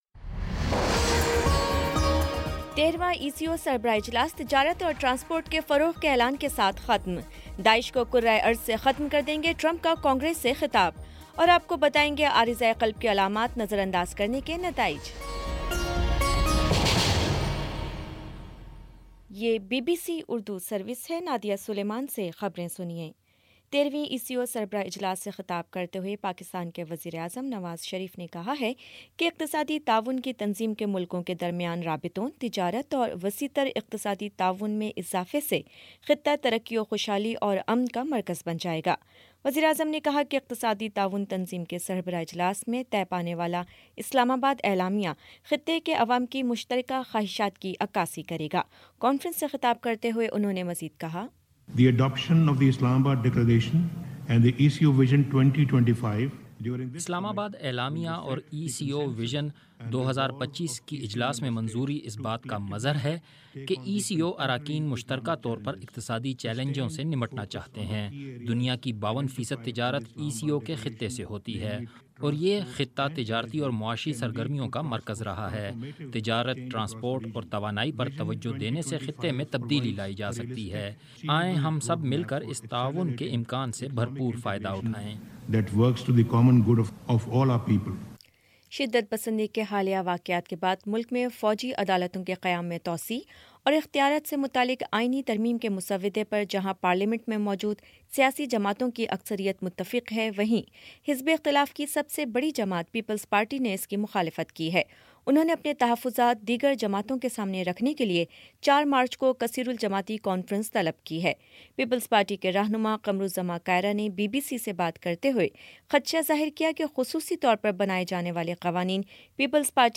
مارچ 01 : شام سات بجے کا نیوز بُلیٹن